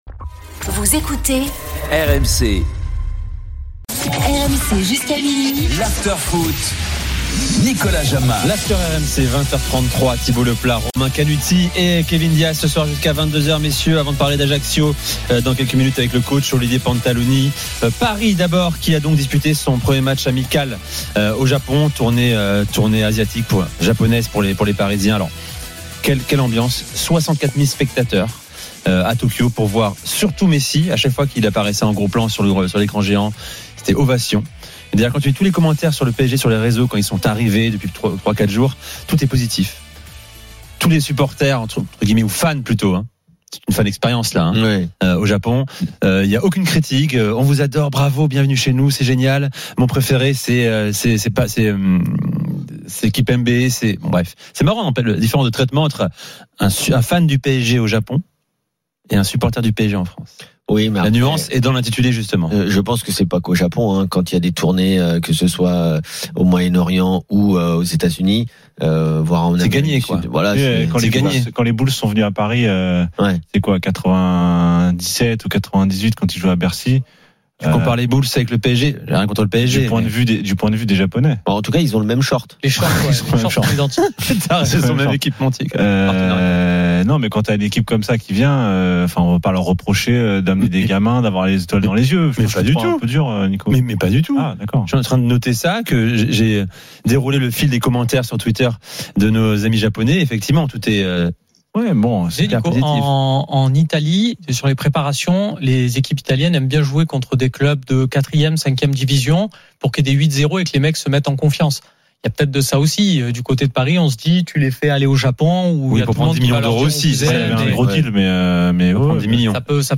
Chaque jour, écoutez le Best-of de l'Afterfoot, sur RMC la radio du Sport !
L'After foot, c'est LE show d'après-match et surtout la référence des fans de football depuis 15 ans !